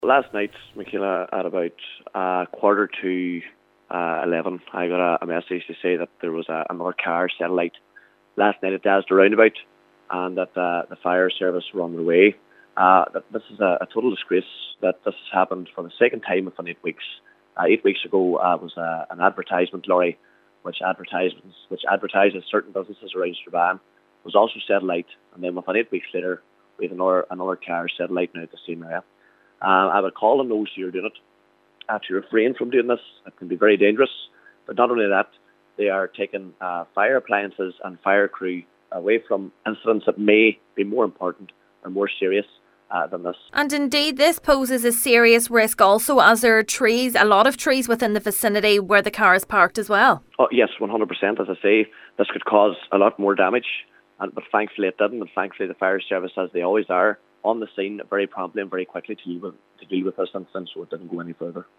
Councillor Jason Barr says thankfully on this occasion further damage was not caused: